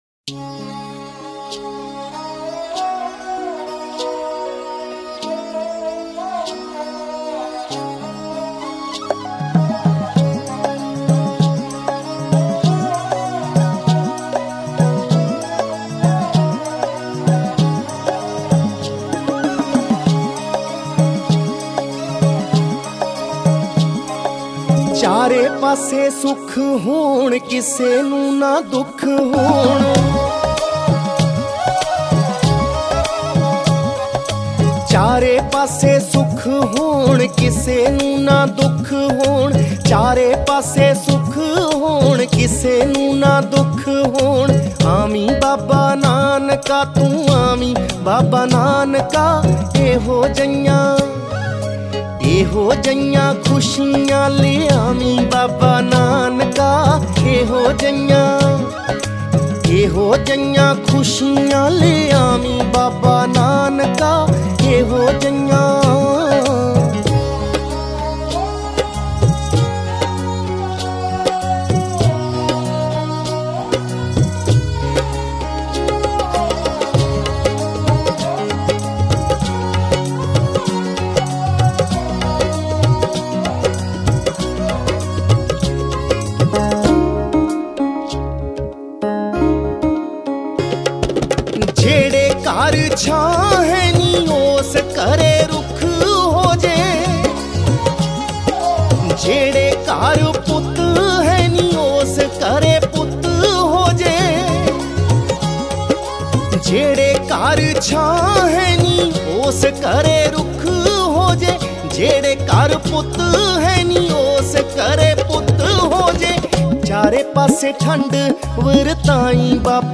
Genre: Sikh Song